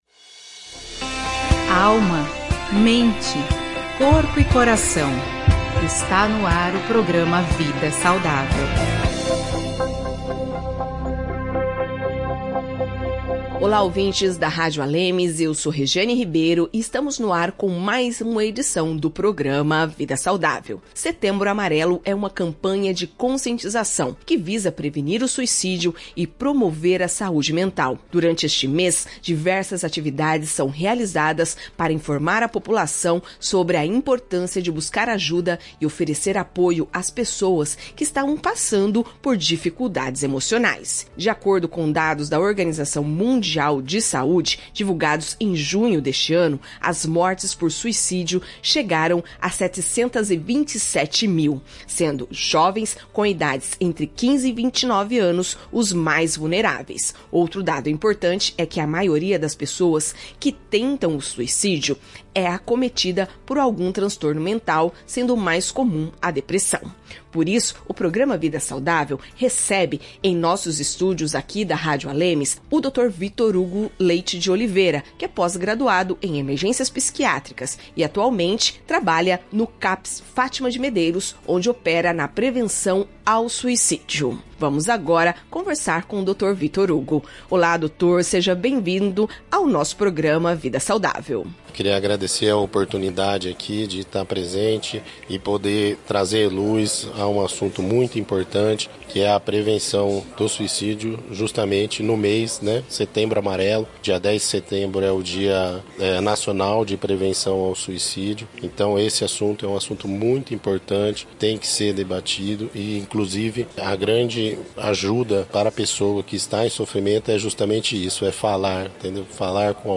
Em entrevista ao programa Vida Saudável